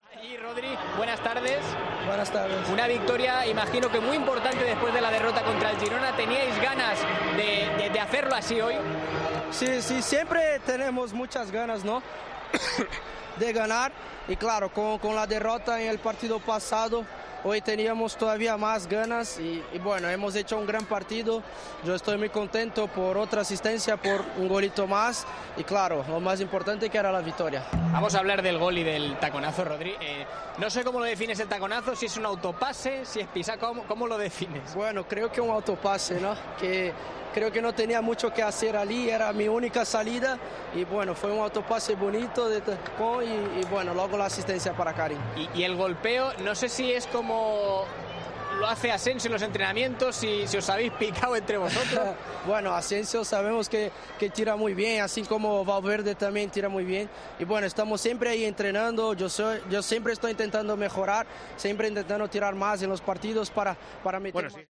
Rodrygo explicó al finalizar el partido su regate afirmando que "era mi única salida".